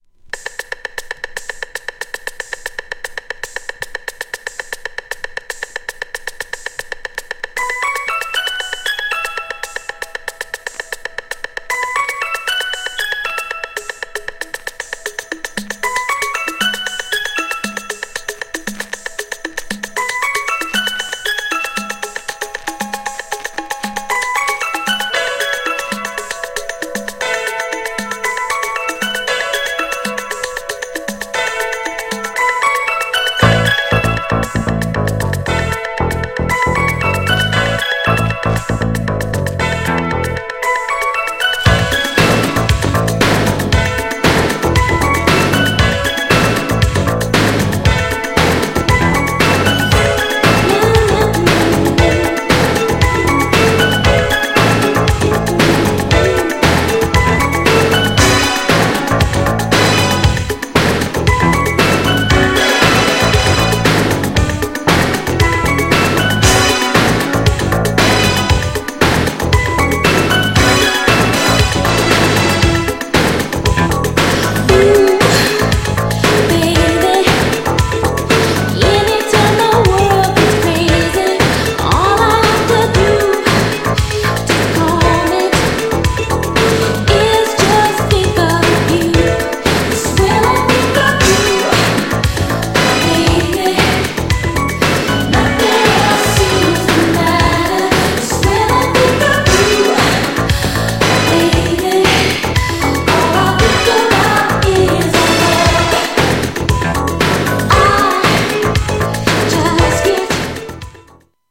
GENRE Dance Classic